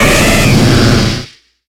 Grito de Feraligatr.ogg
Grito_de_Feraligatr.ogg